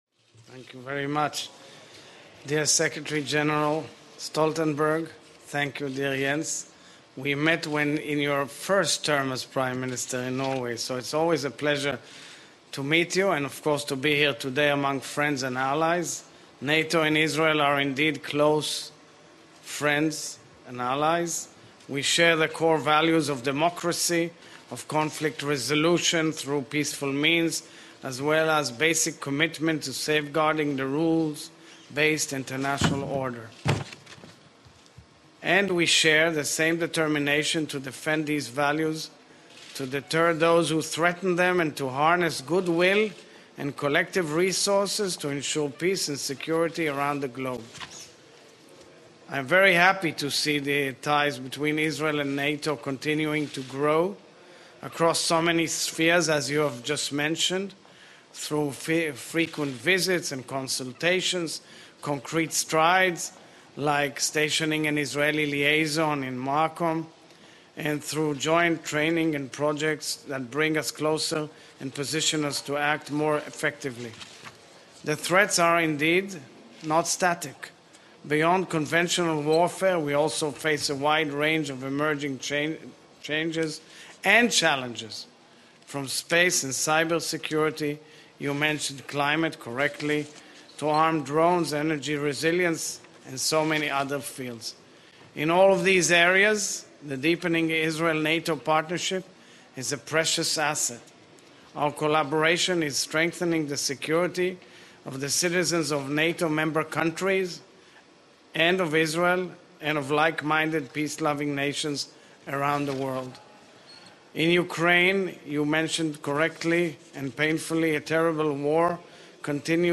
President Isaac Herzog
Press Statement at NATO Headquarters
delivered 26 January 2023, Brussels, Belgium